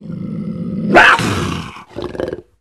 Звук пумы для видео монтажа